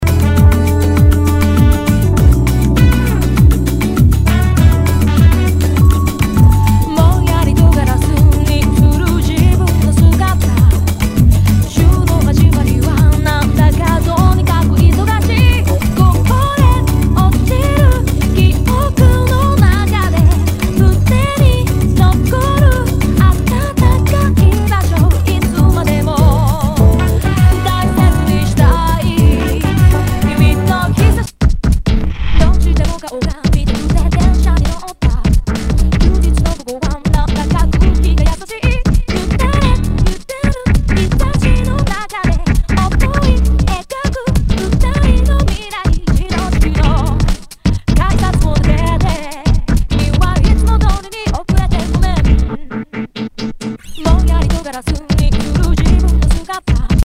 HOUSE/TECHNO/ELECTRO
ディープ・ヴォーカル・ハウス！